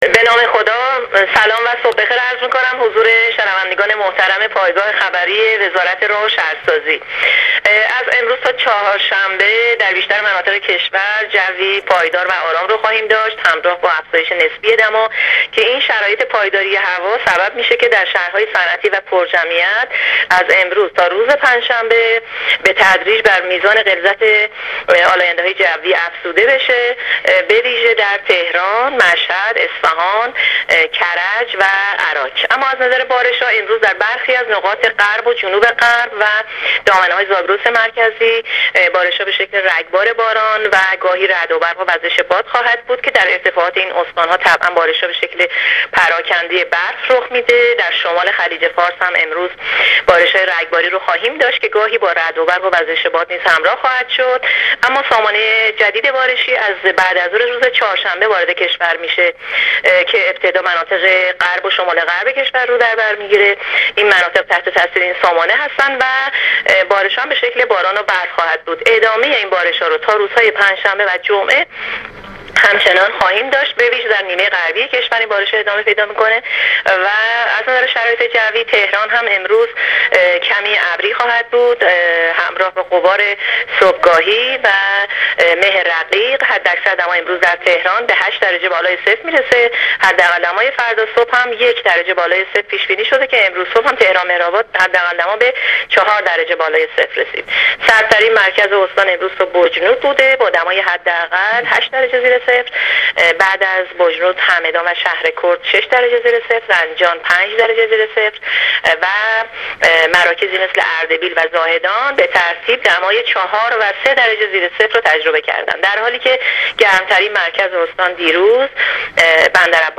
گزارش رادیو اینترنتی از آخرین وضعیت آب‌و‌هوای امروز